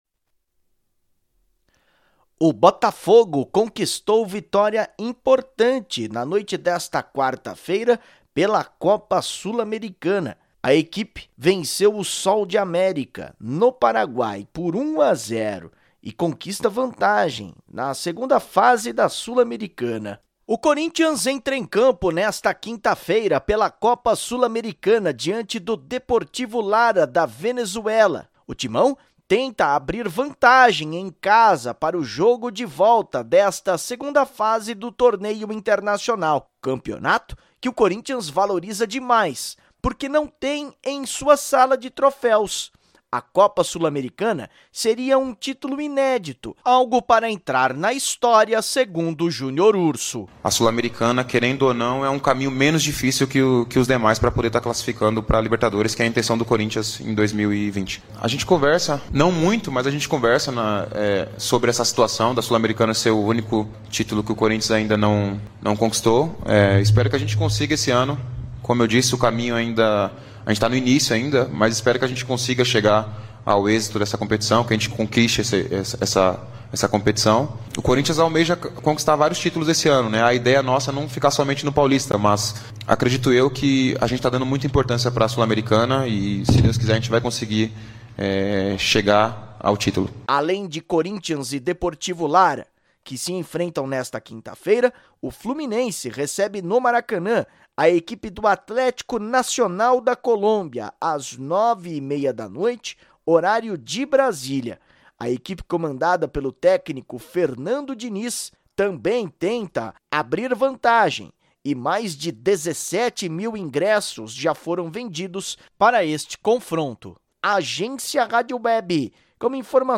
Corinthians e Fluminense entram em campo pela Copa Sul-Americana Clique no “Play” e Ouça a Reportagem